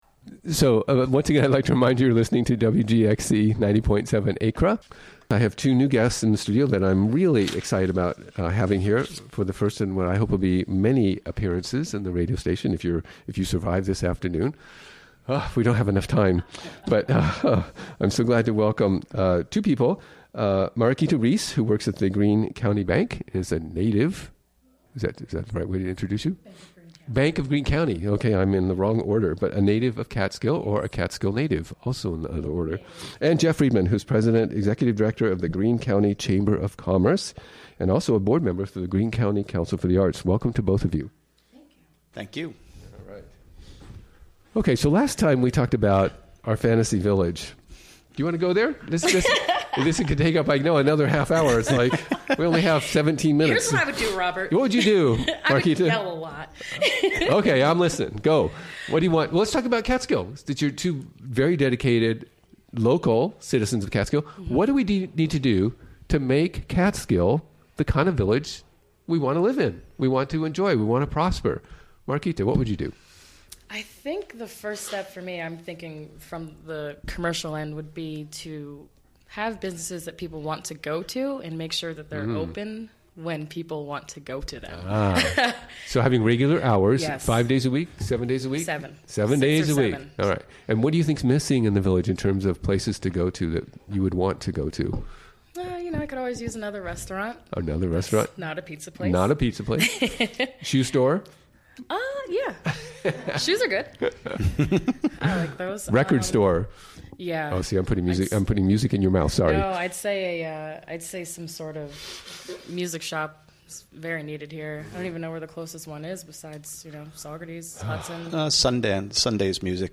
Recorded during the WGXC Afternoon Show of Tuesday, March 21, 2017.